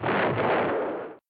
explosion3.ogg